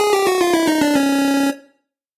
Звуки Game Over
ретро стиль